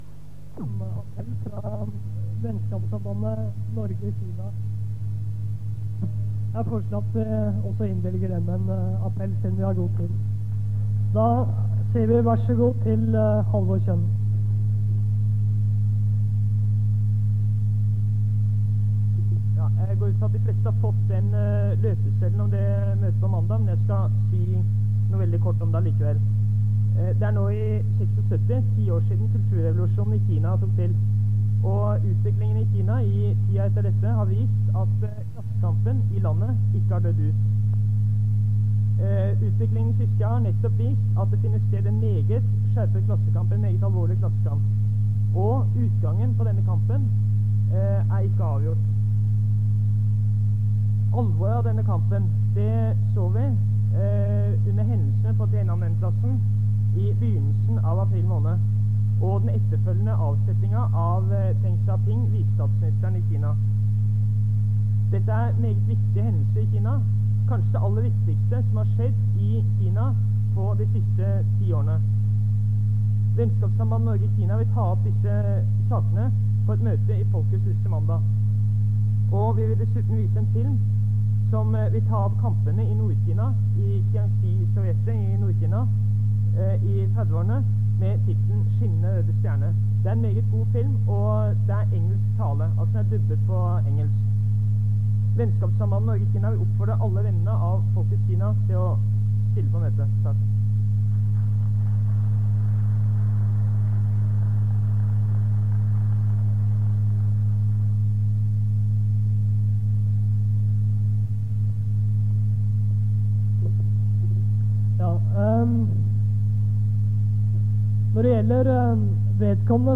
Det Norske Studentersamfund, Generalforsamling, 08.05.1976